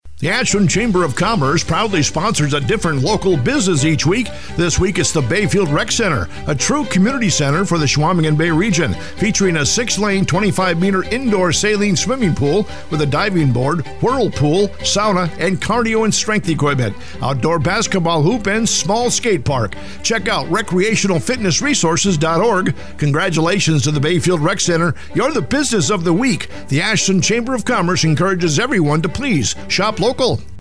Each week the Ashland Area Chamber of Commerce highlights a business on Heartland Communications radio station WATW 1400AM and Bay Country 101.3FM. The Chamber draws a name at random from our membership and the radio station writes a 30-second ad exclusively for that business.